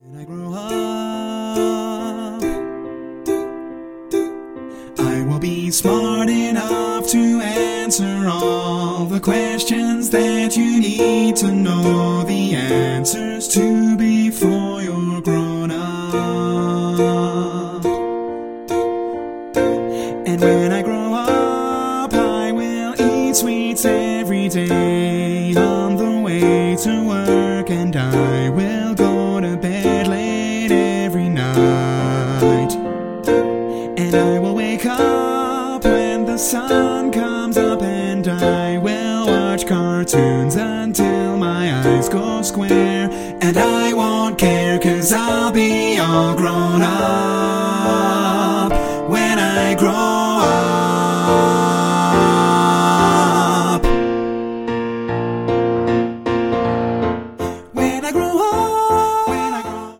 Mixed
SATB with piano accomp.